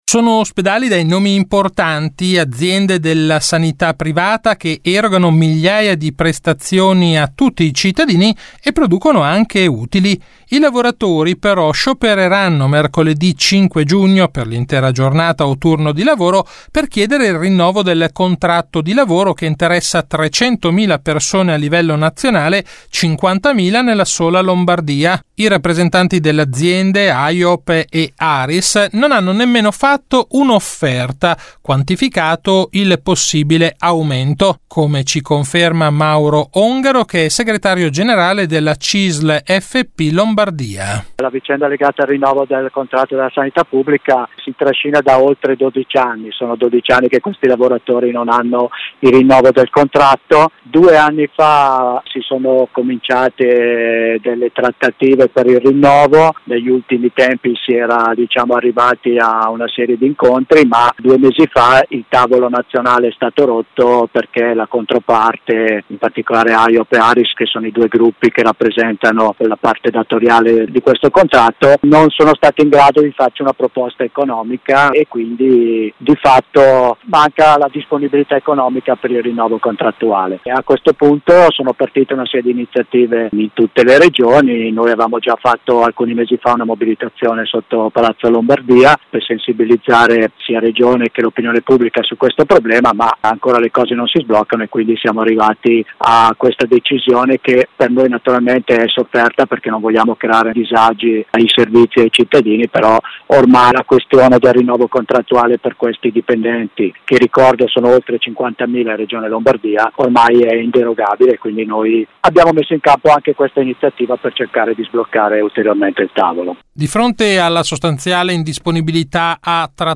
Di seguito la puntata del 23 maggio di RadioLavoro, la rubrica d’informazione realizzata in collaborazione con l’ufficio stampa della Cisl Lombardia e in onda tutti i giovedì alle 18.20 su Radio Marconi in replica il venerdì alle 12.20.